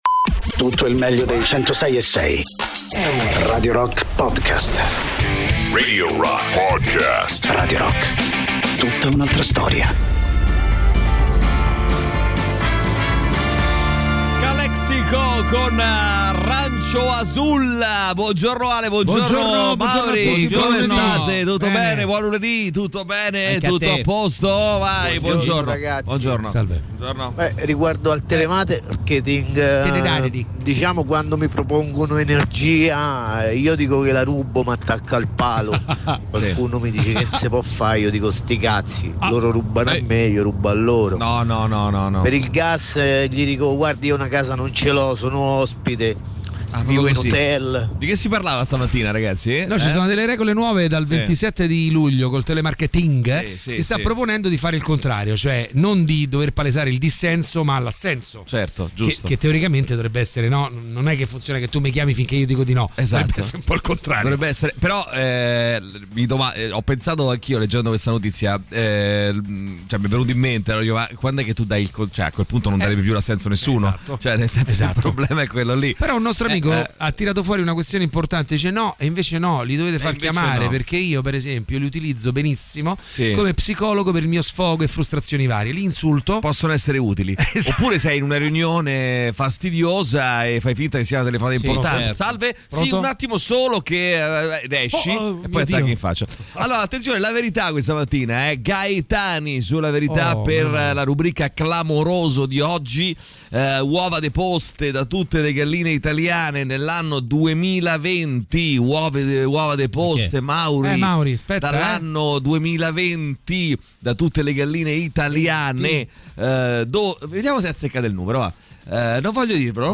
in diretta dal lunedì al venerdì dalle 6 alle 10 sui 106.6 di Radio Rock.